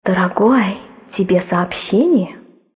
/128kbps) Описание: Женский,нежный голос!Классно на sms!